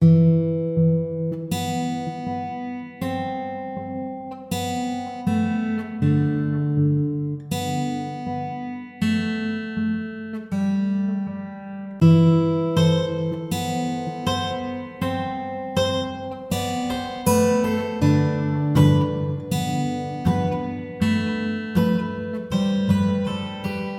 寒冷的吉他
描述：原声吉他循环
Tag: 80 bpm Chill Out Loops Guitar Acoustic Loops 4.04 MB wav Key : E